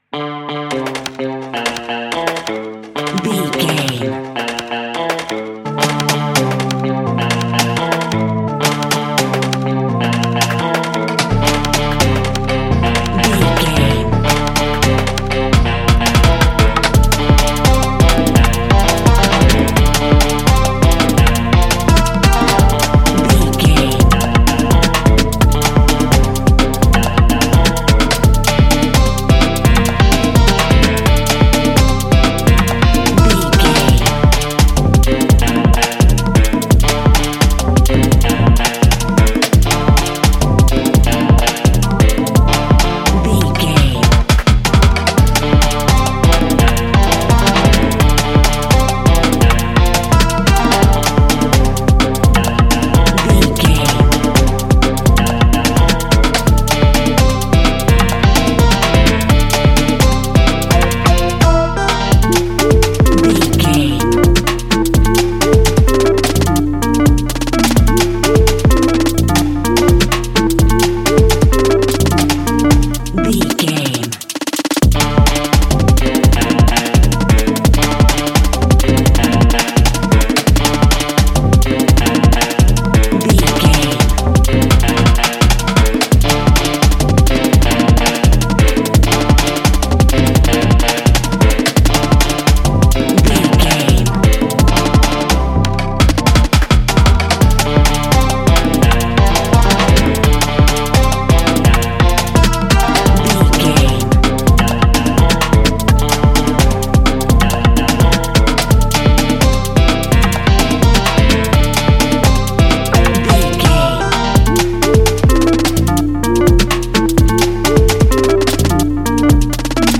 rebellious guitars and driving African percussion
Fast paced
Ionian/Major
energetic
Rhythmic
Pulsating